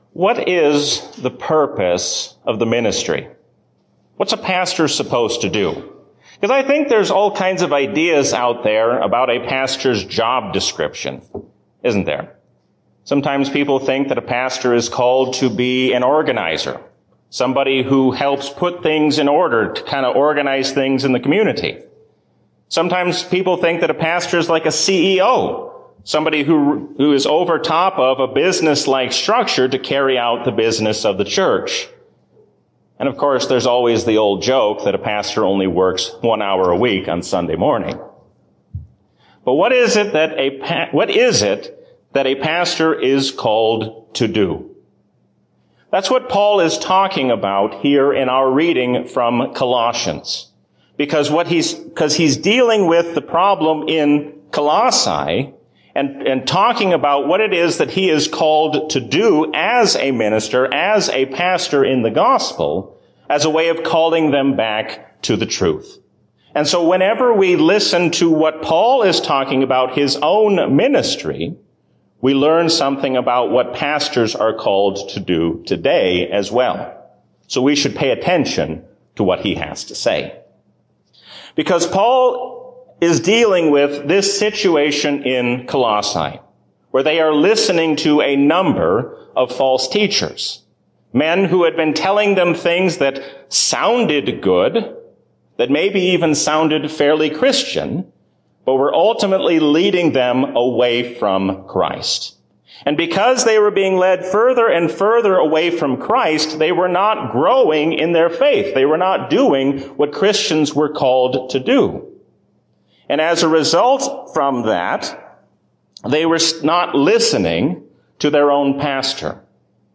Sermon
A sermon from the season "Trinity 2021." Stand firm against worldly powers, because Jesus reigns as King forever.